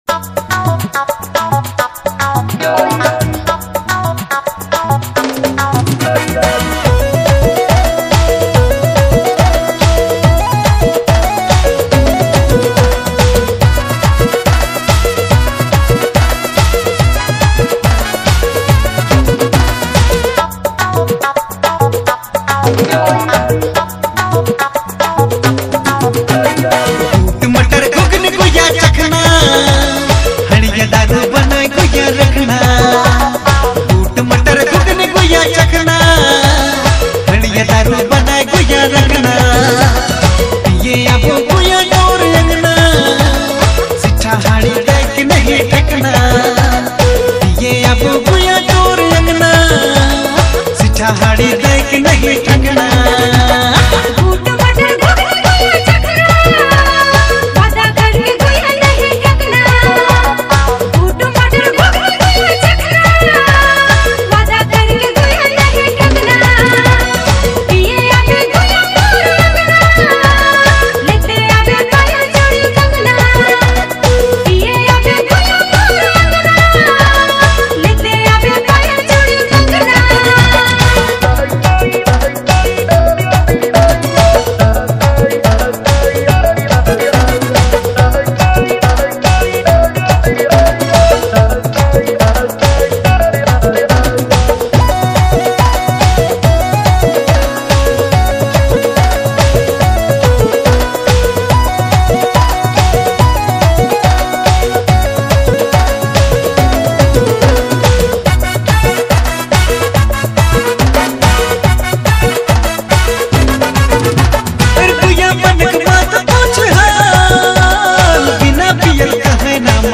Nagpuri music